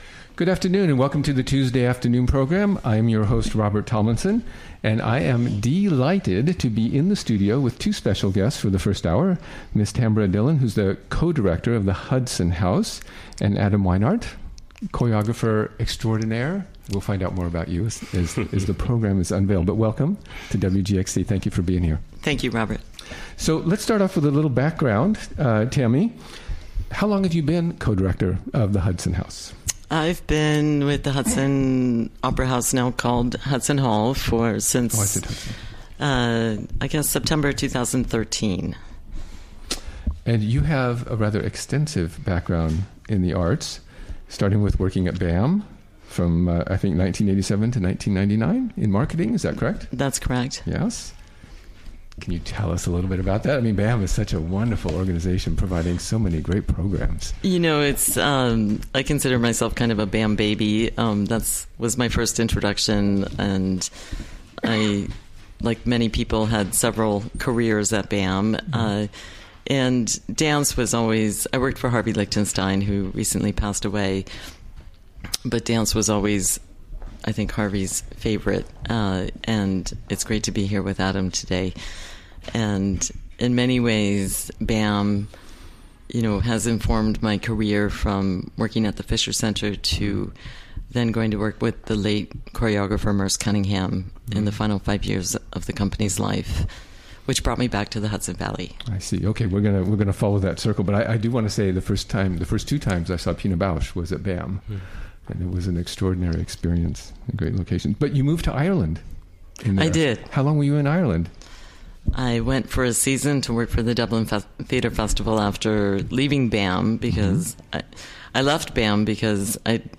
Recorded during the WGXC Afternoon Show Tuesday, April 11, 2017.